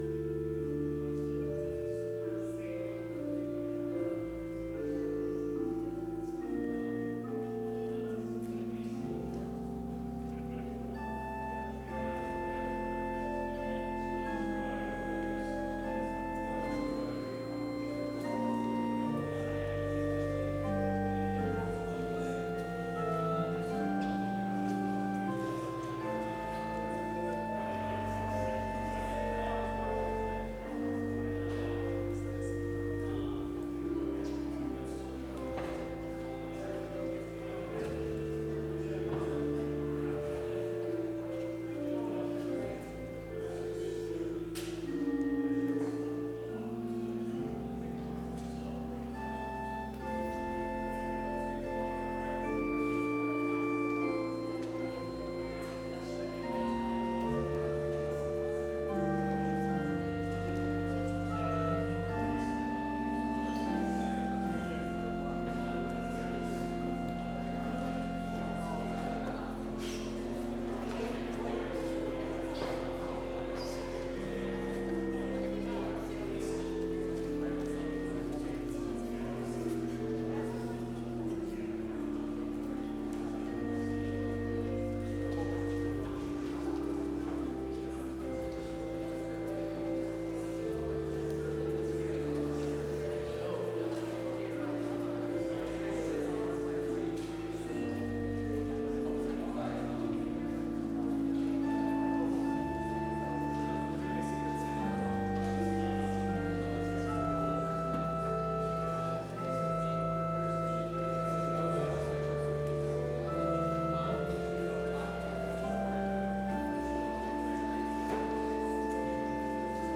Complete service audio for Chapel - Monday, December 1, 2025